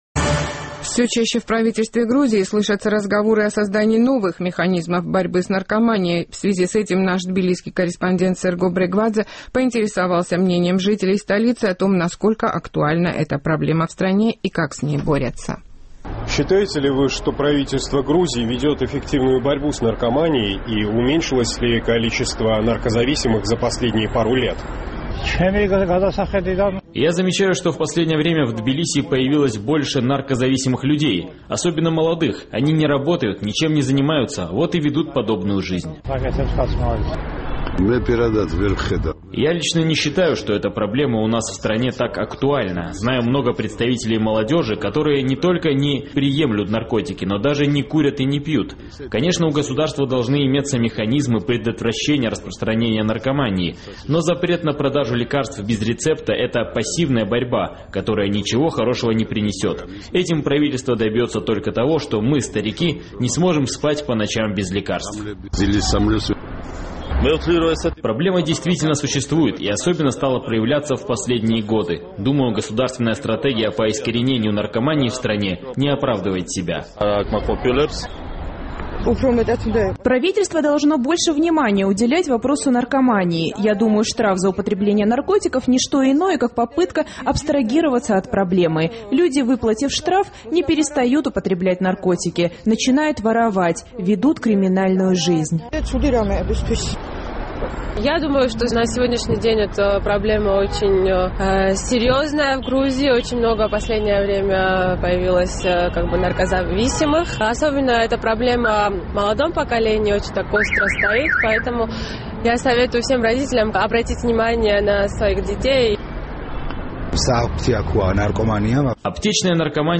Все чаще в правительстве Грузии слышатся разговоры о создании новых механизмов борьбы с наркоманией. В связи с этим наш тбилисский корреспондент поинтересовался мнением жителей столицы о том, насколько актуальна эта проблема в стране и как с ней бороться.